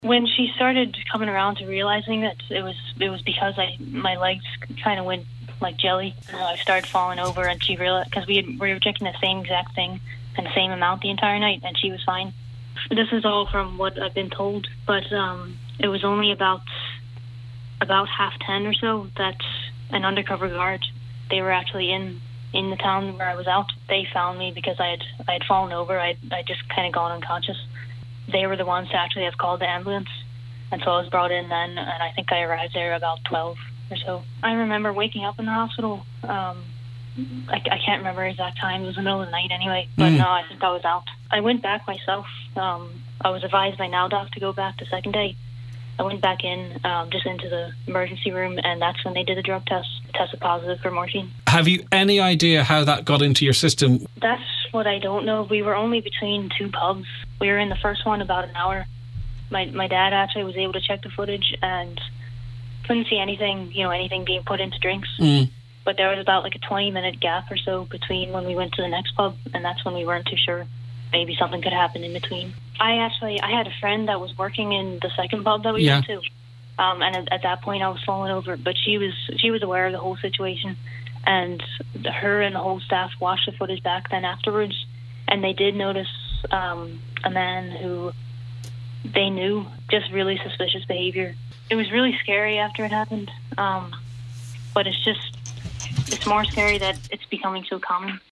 A young woman has spoken out after allegedly being spiked in Donegal a number of weekends ago.